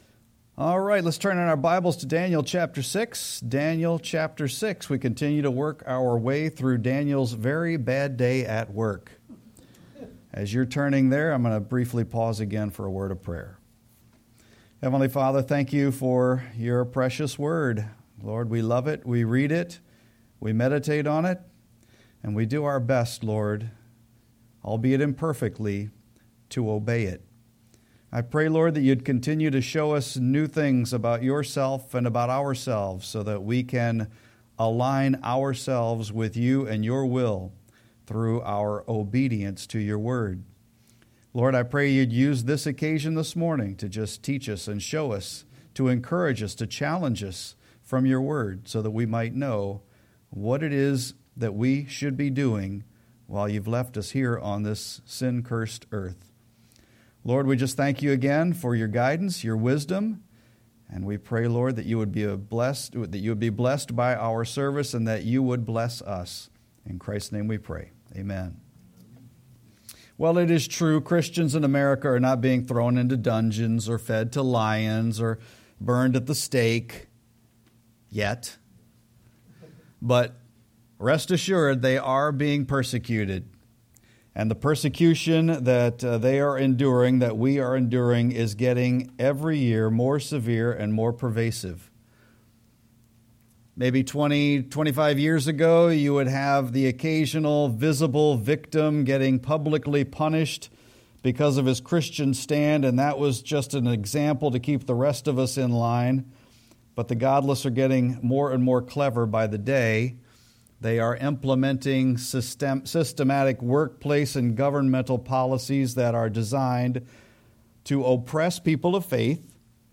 Sermon-11-3-24.mp3